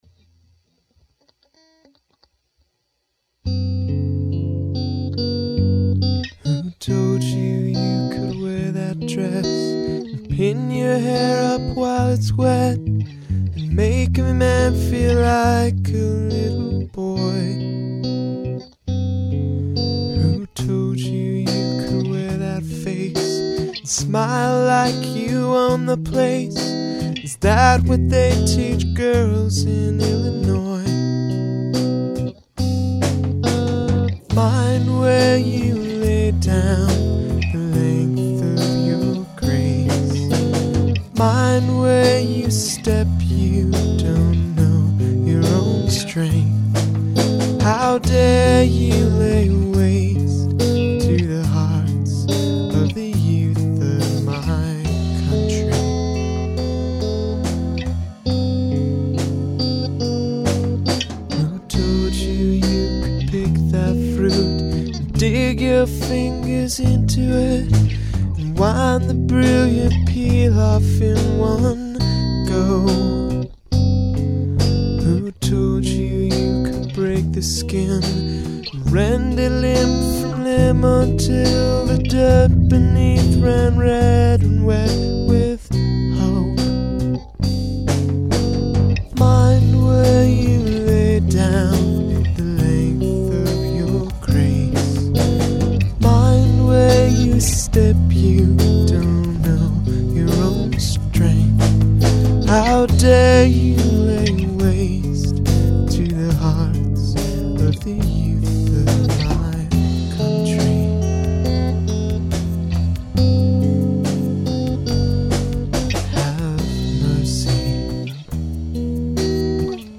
It's in B Major.
A B A B A' (Came during my economical phase.)